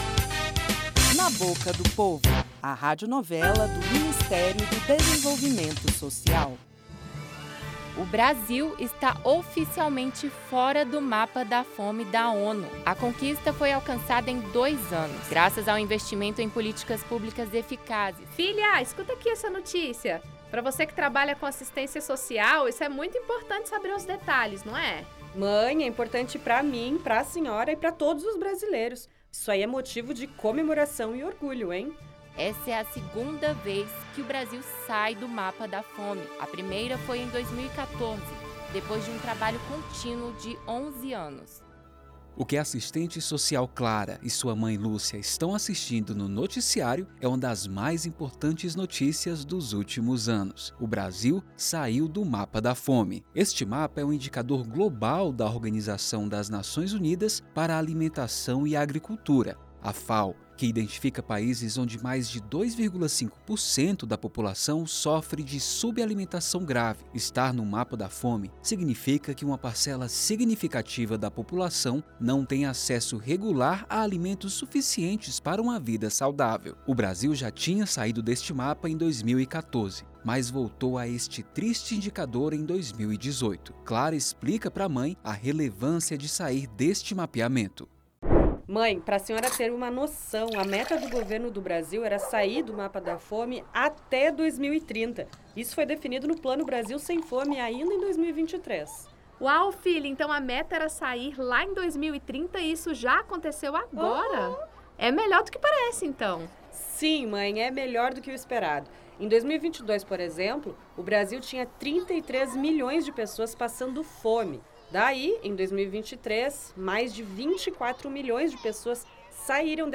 Na Boca do Povo - Radionovela
No novo episódio da radionovela Na Boca do Povo, uma assistente social e sua mãe conversam sobre a Saída do Brasil do Mapa da Fome. A notícia é motivo de orgulho para todo o país.